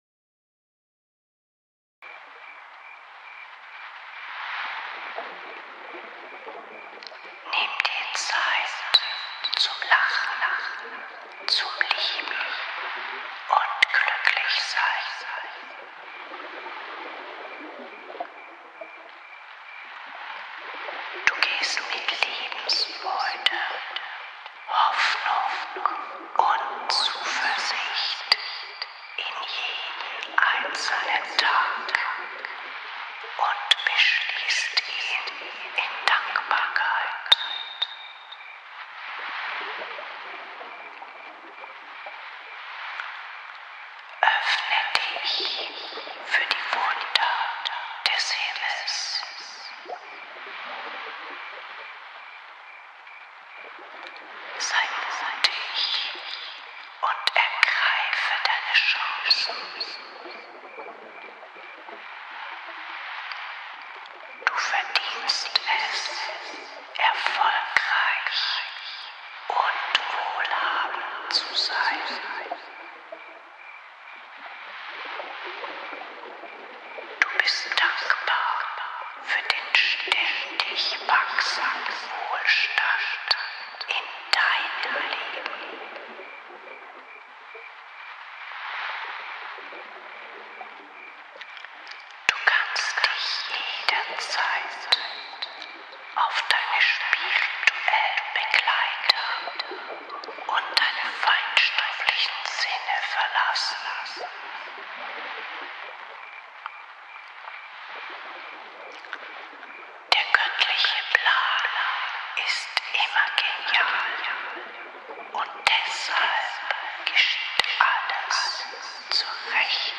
Liebevolle Affirmationen in ASMR-Technik